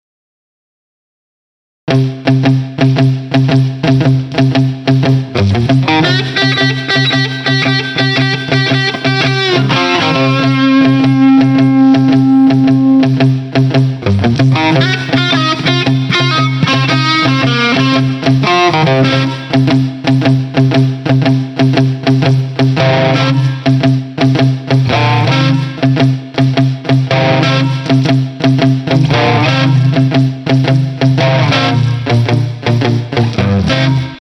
Вниз  Играем на гитаре
Прям джаз :джаз:
А что за стук?
Это пальм мьютинг